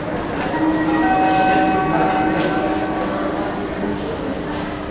ding_dong_ding
announcement central chord ding dong dutch rotterdam signal sound effect free sound royalty free Sound Effects